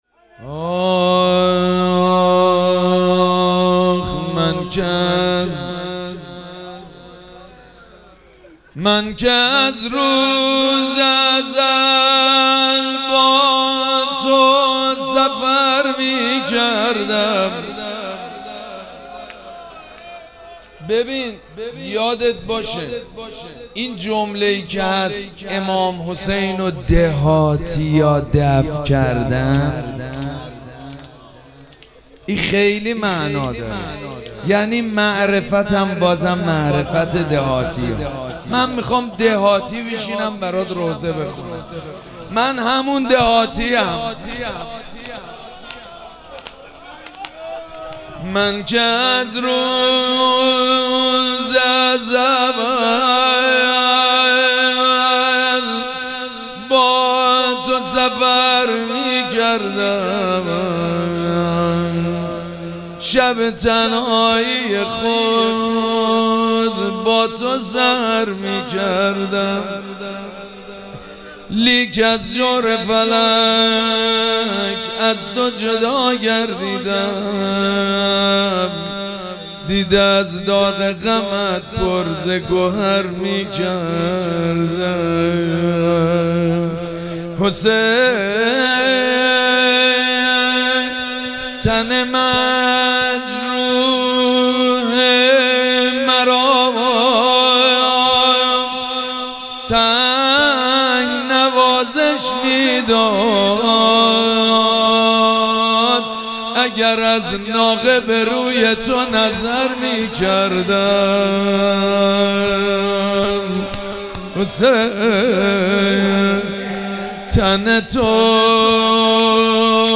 شب پنجم مراسم عزاداری اربعین حسینی ۱۴۴۷
روضه
مداح